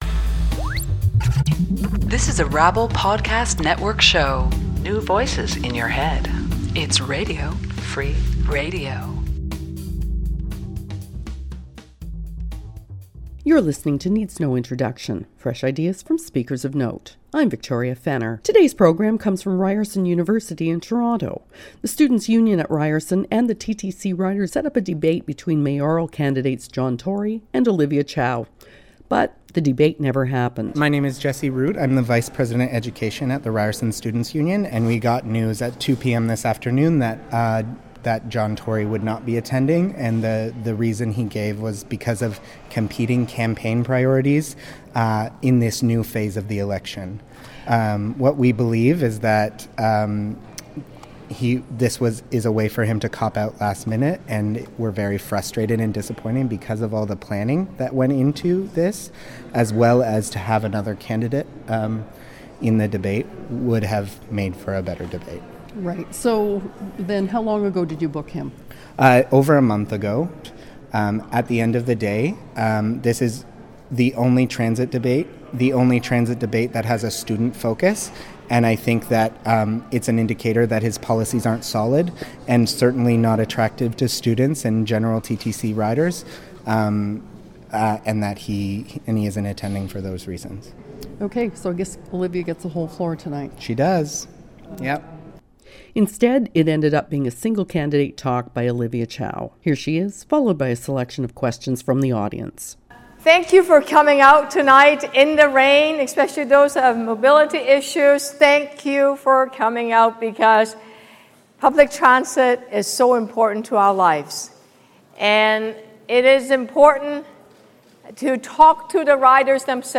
Mayoral Candidate Olivia Chow speaks about Transit in Toronto
An event cosponsored by the Ryerson Students' Union and TTCRiders